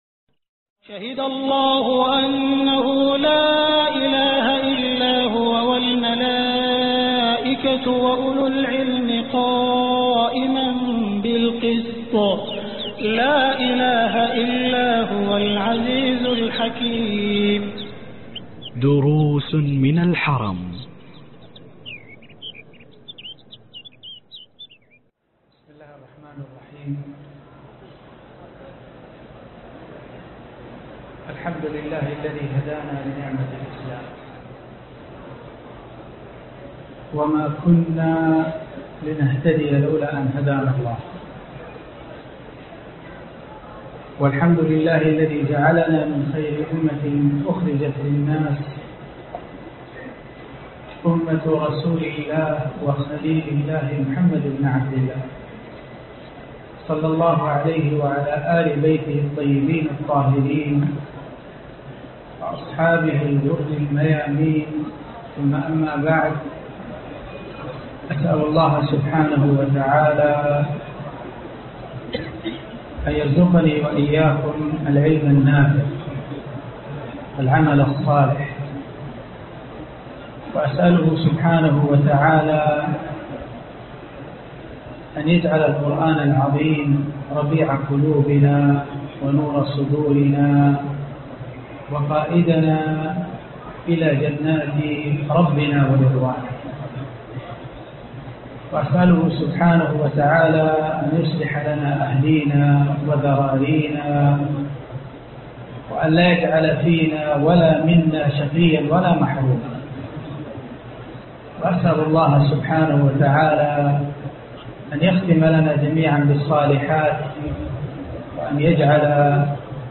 الدرس 23 وقفات في سورة البقرة ج1 (دروس من الحرم)